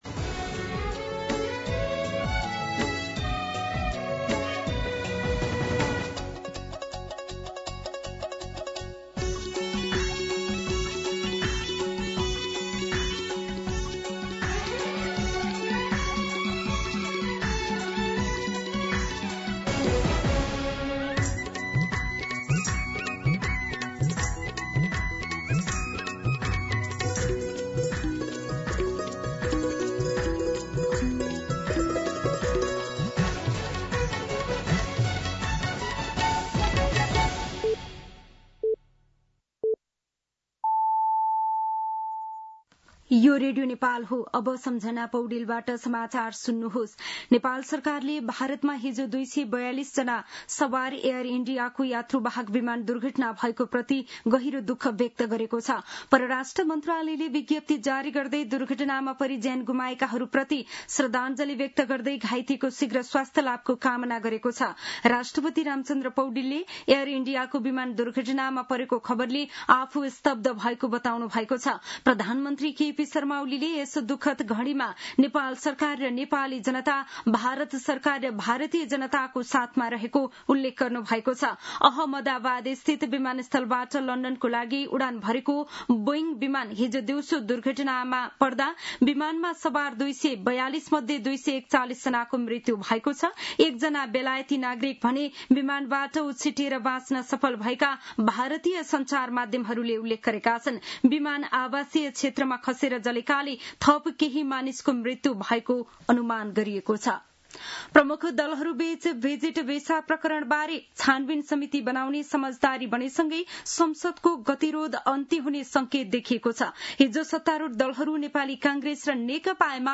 मध्यान्ह १२ बजेको नेपाली समाचार : १८ पुष , २०२६